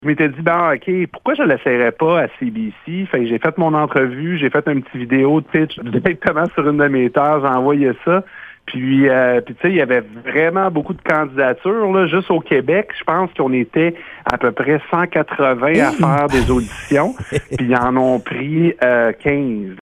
ENTREVUE-BOSAPIN-nbr-candidature.mp3